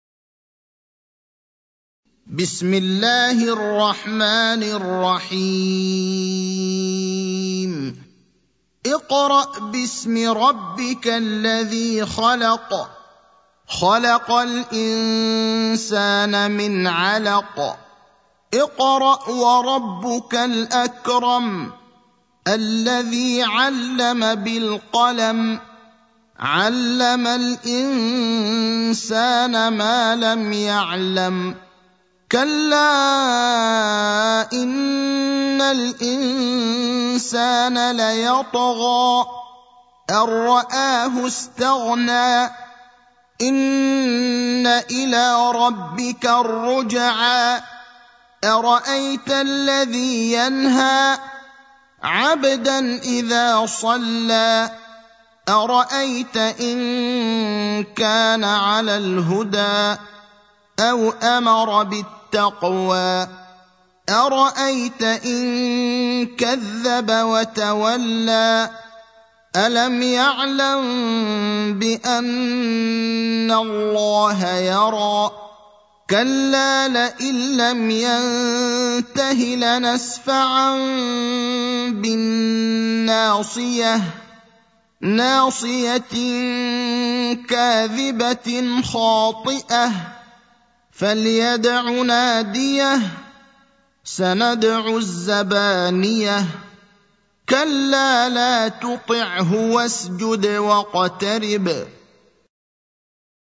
المكان: المسجد النبوي الشيخ: فضيلة الشيخ إبراهيم الأخضر فضيلة الشيخ إبراهيم الأخضر العلق (96)